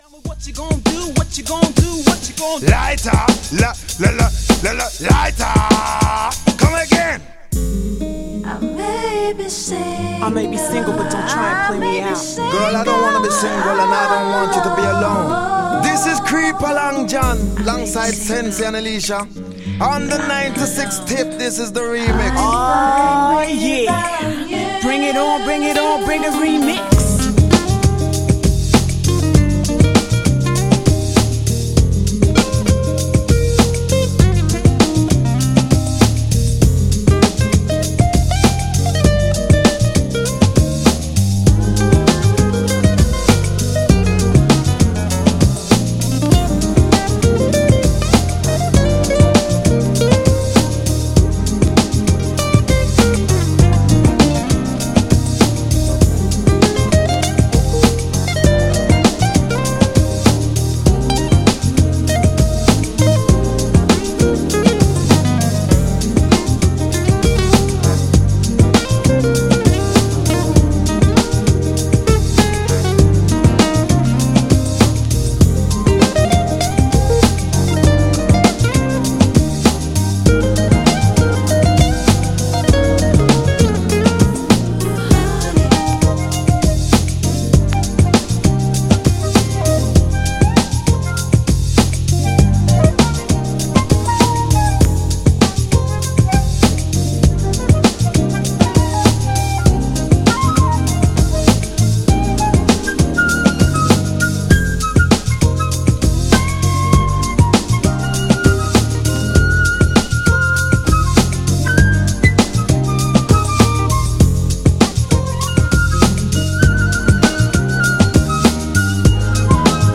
GENRE R&B
BPM 96〜100BPM
POPな歌モノ
キャッチーなR&B
女性VOCAL_R&B